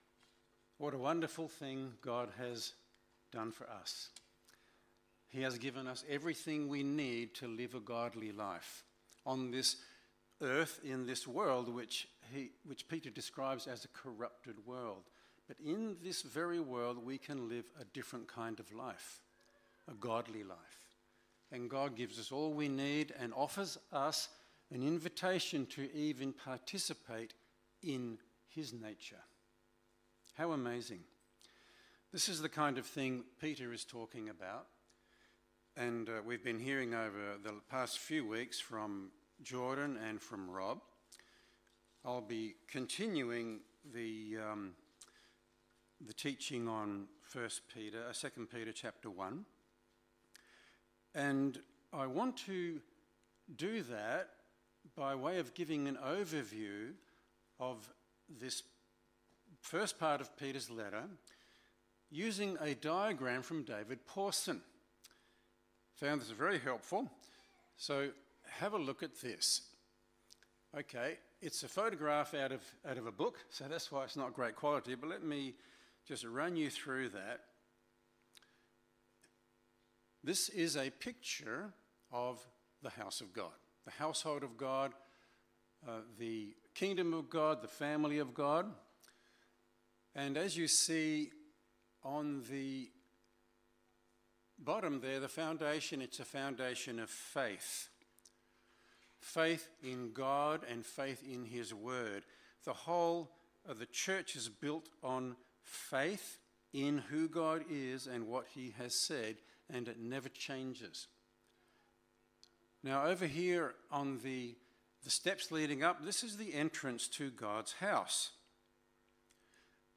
Sermons | Hope Christian Centre